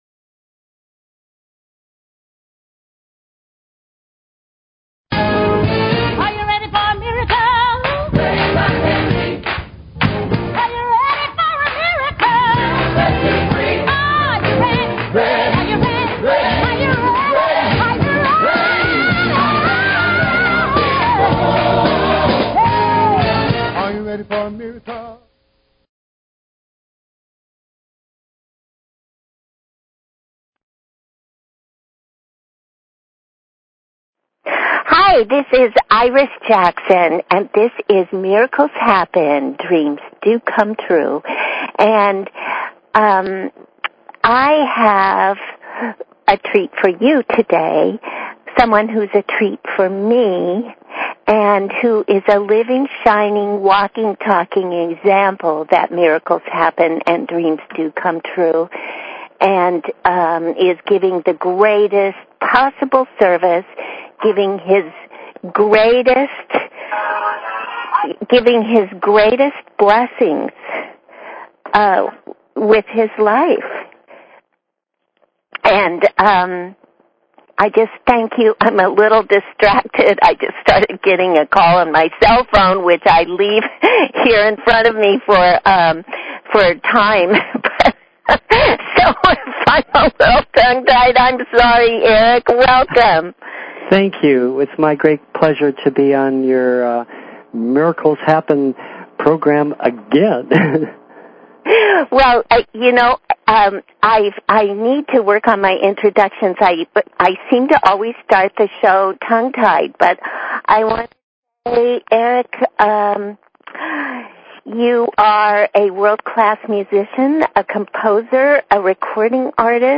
Talk Show Episode, Audio Podcast, Miracles_Happen and Courtesy of BBS Radio on , show guests , about , categorized as